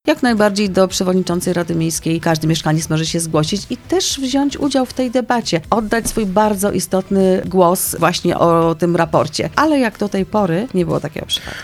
Mówi Magdalena Lasota, radna miasta Radomia: